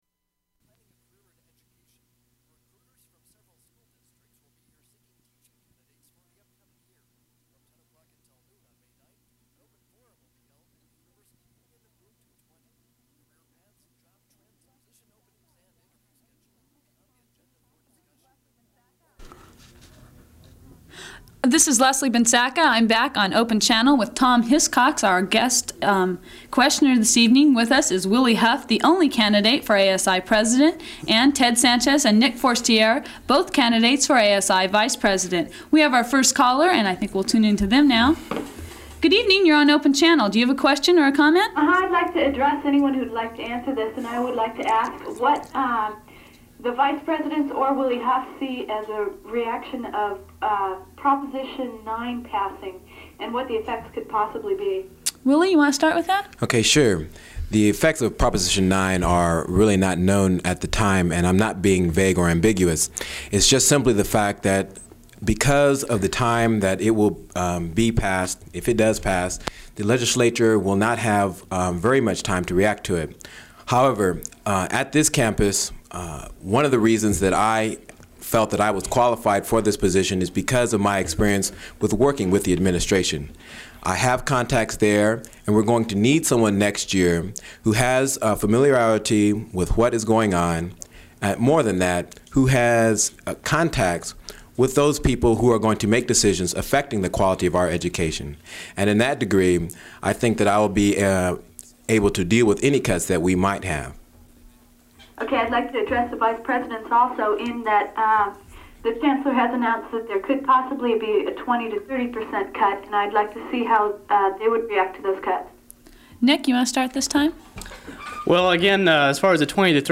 Caller asks about the use of alcohol on campus